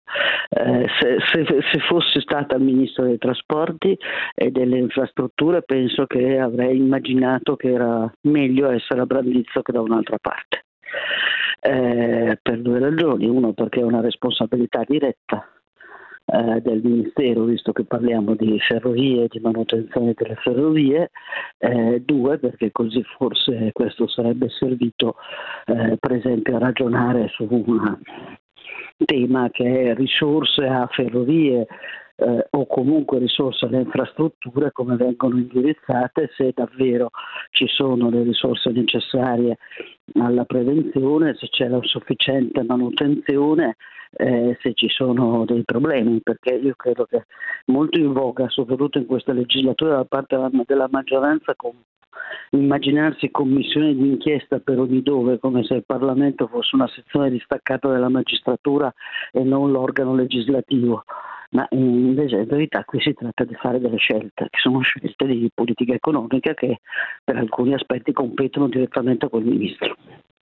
Un grave errore dice l’ex segretaria della Cgil, oggi nel Pd, Susanna Camusso: